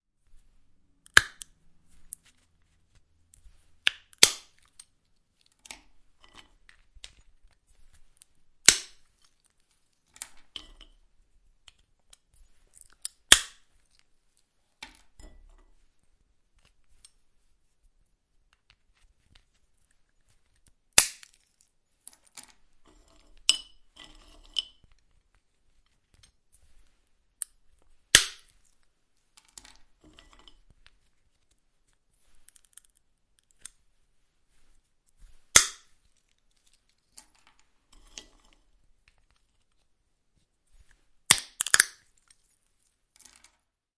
Щелкунчик и звук раскола орехов